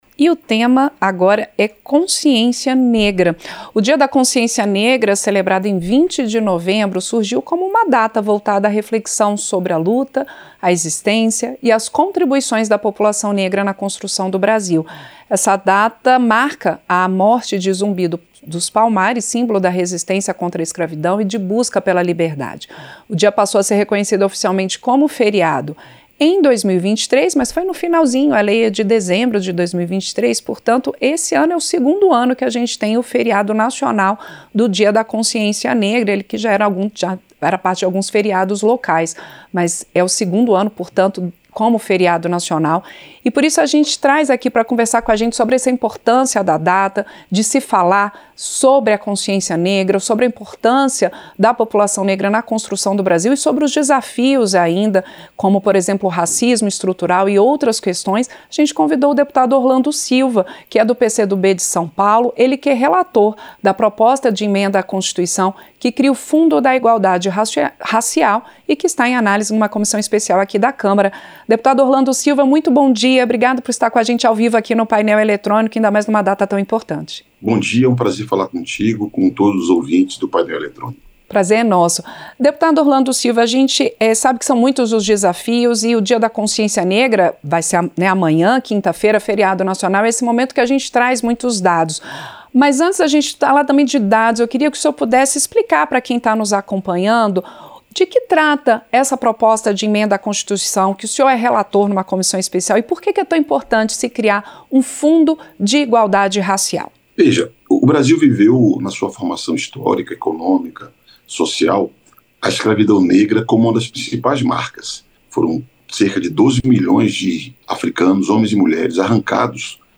Entrevista - Dep. Orlando Silva (PCdoB-SP)